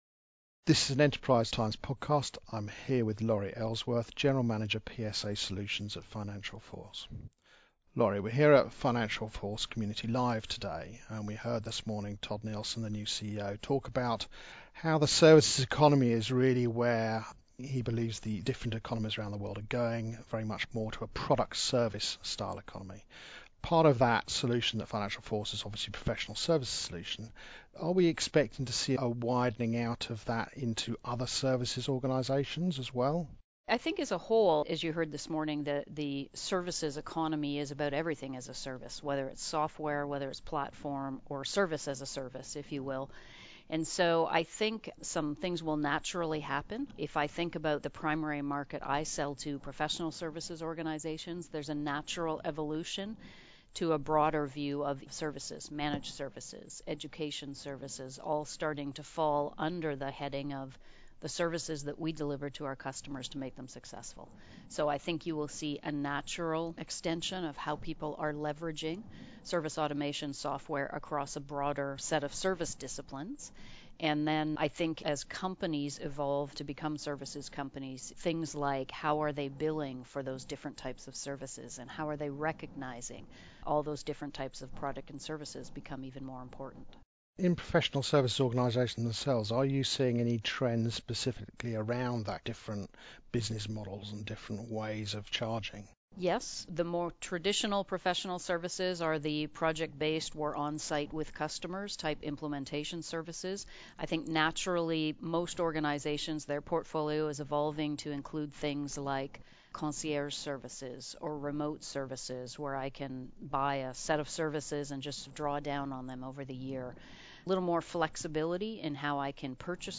Community Live, London, May 17th 2017